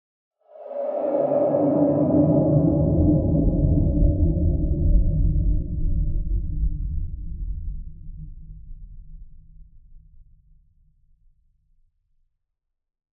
skyclad_sound_whoosh_resonant_wind_to_rumble_bass_159
Tags: ghost